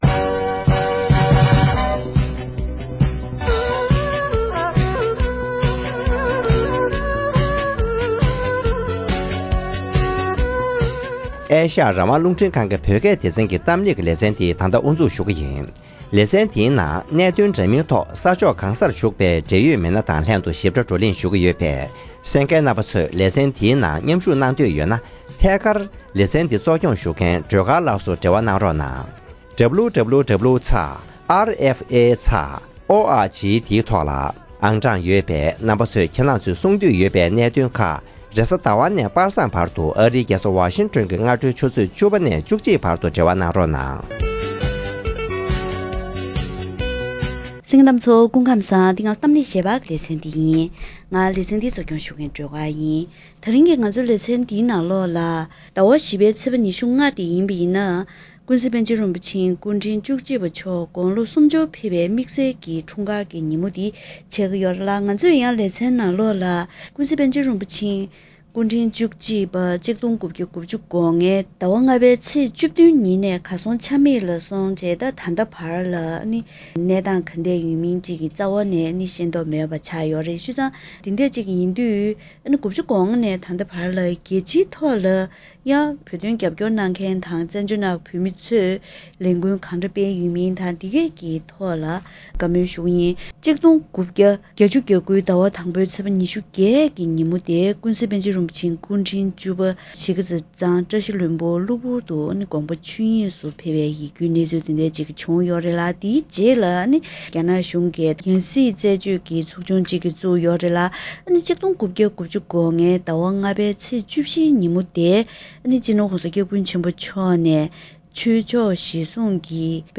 པཎ་ཆེན་རིན་པོ་ཆེ་དགུང་གྲངས་སུམ་ཅུར་ཕེབས་ཡོད་ཀྱང་ད་དུང་ཡང་གར་ཡོད་གནས་ཚུལ་གང་ཡང་མེད་པའི་སྐོར་གླེང་མོལ།